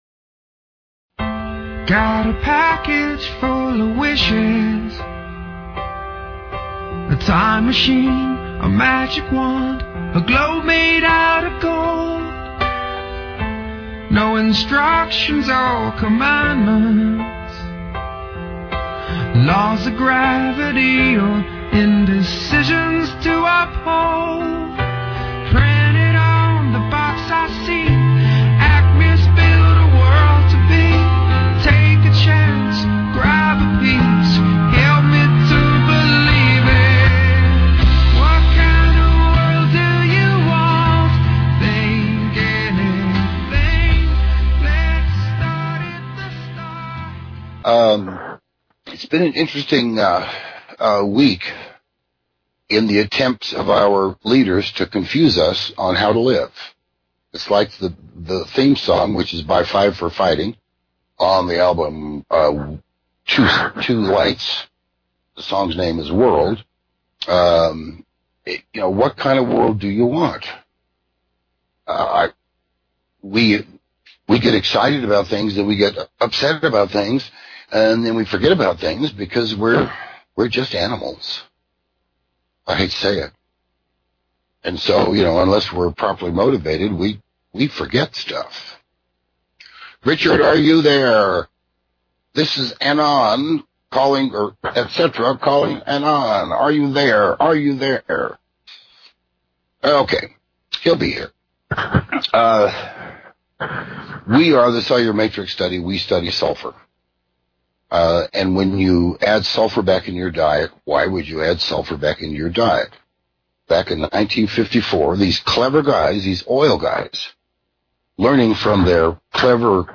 In an archived Radio RMN podcast that was recorded in 2011
Play Podcast Interview made available with permission of Radio RMN .